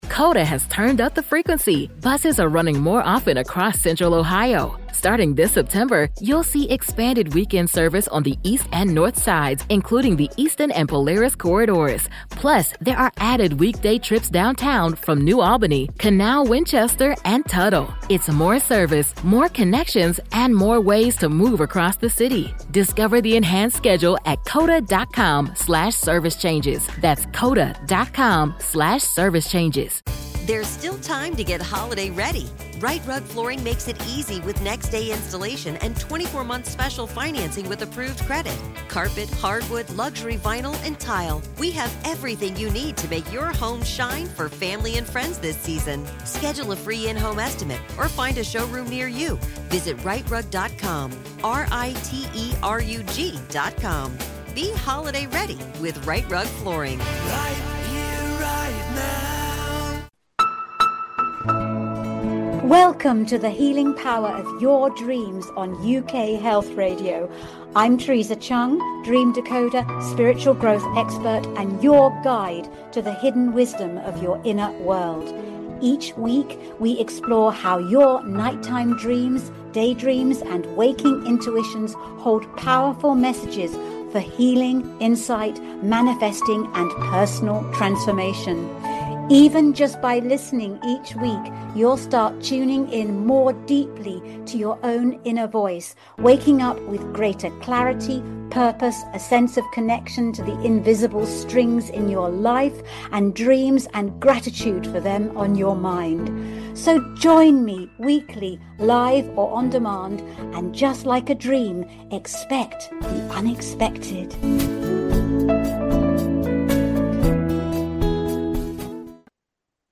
The Healing Power of Dreams explores the transformative power of nocturnal dreams and day dreams for your mental, emotional, physical, and spiritual wellbeing. Each live episode dives deep with leading scientists, psychologists, authors, and consciousness researchers, plus the occasional celebrity guest sharing their own dream stories. With a unique blend of science and spirituality, the show encourages listener interaction, dream story sharing, and practical advice on how to decode and harness your dreams for personal and spiritual growth.